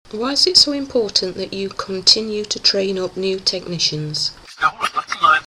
Sony B300 and reversed Bulgarian background